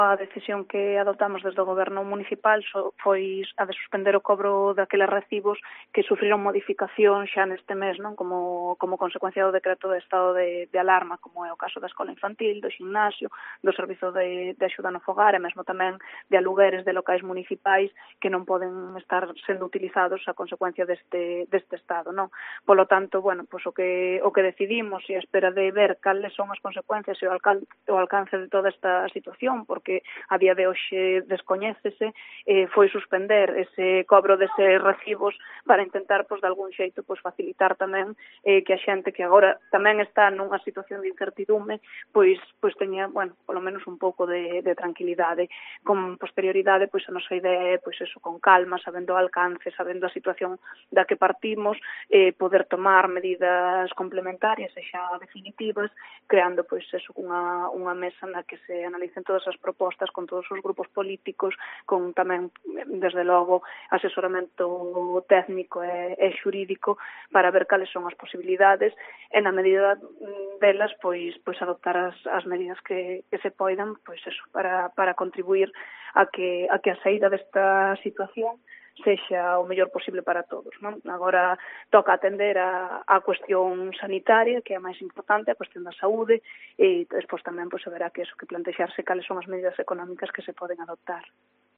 Declaraciones de la alcaldesa de Barreiros, Ana Ermida